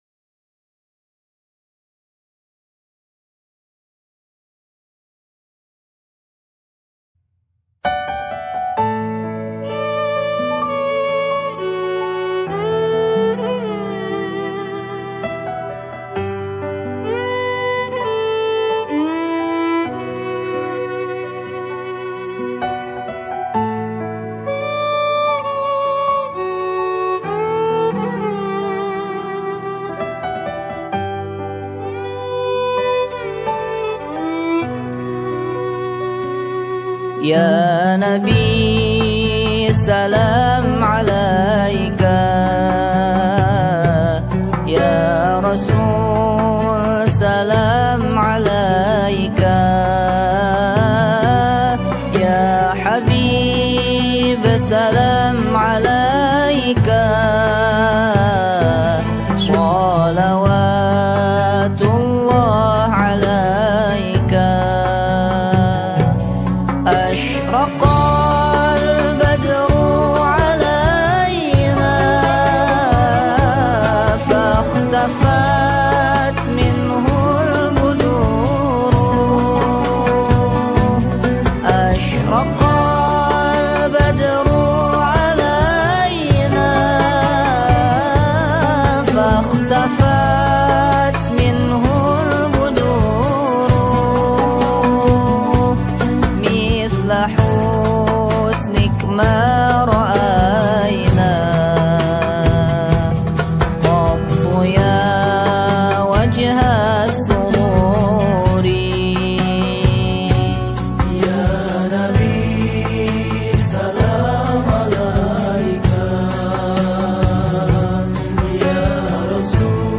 Indonesian Sufi Music